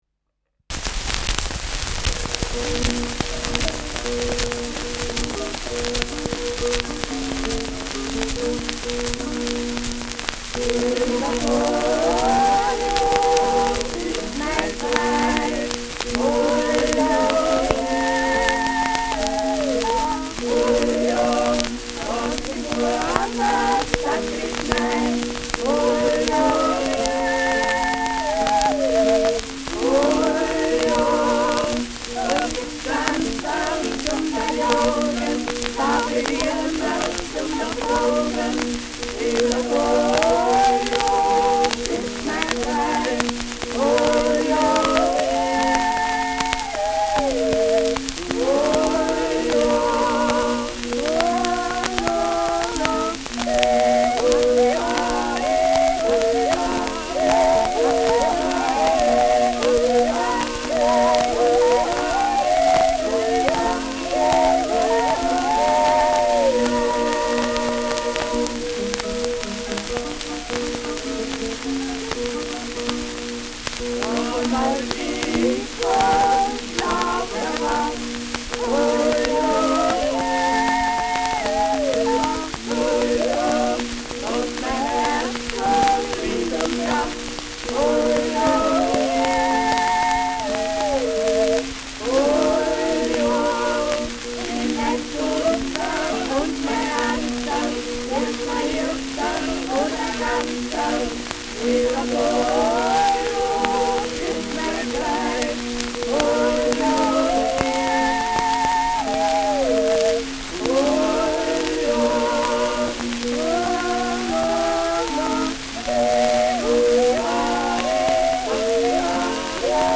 Schellackplatte
starkes Rauschen : präsentes Knistern : Nadelgeräusch : abgespielt : leiert : häufiges Knacken
Zugspitzsänger (Interpretation)
[München] (Aufnahmeort)